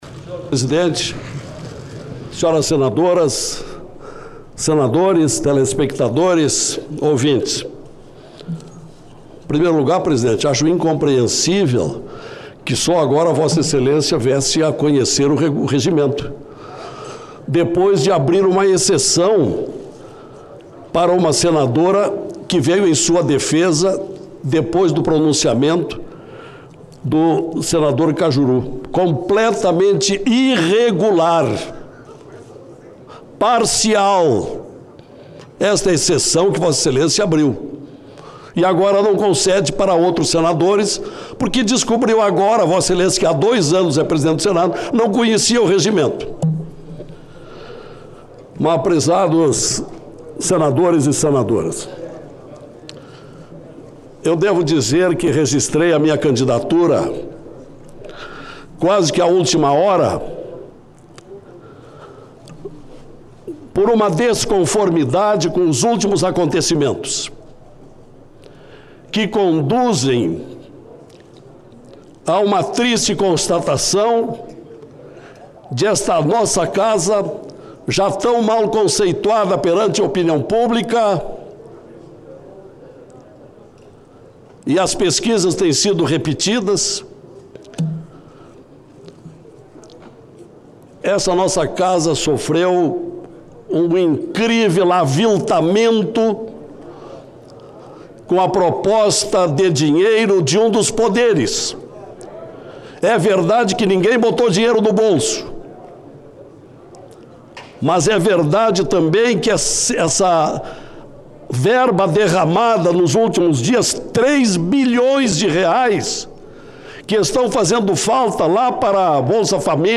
O senador Lasier Martins (Podemos-RS) afirmou que houve intensa troca de favores no processo de escolha do presidente do Senado. Ele disse que o uso de verbas do Orçamento para beneficiar parlamentares que votem no candidato do governo prejudica a independência do Senado. Ouça o áudio com o pronunciamento de Lasier.